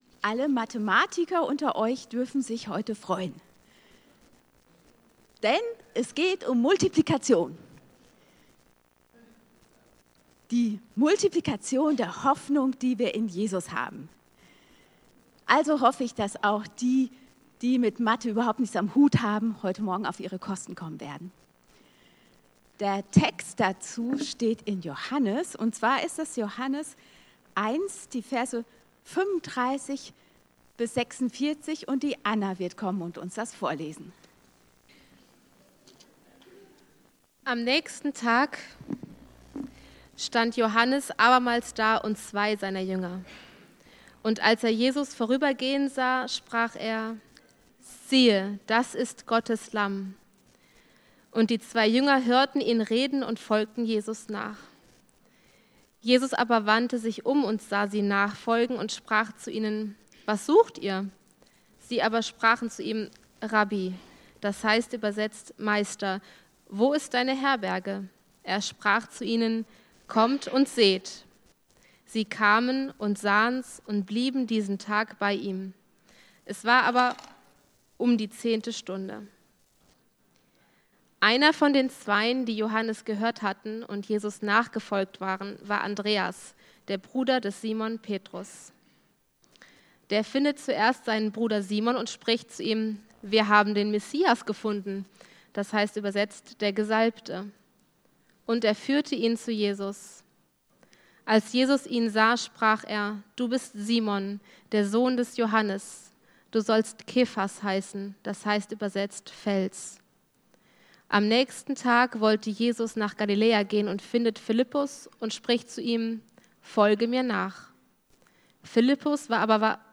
Hoffnung vermehrt sich, wenn Du sie teilst. In ihrer Predigt vom 16.